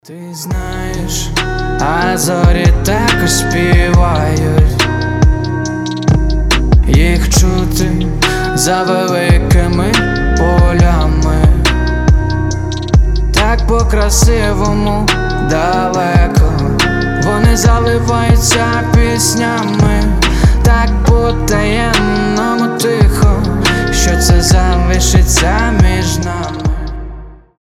• Качество: 320, Stereo
красивый мужской голос
романтичные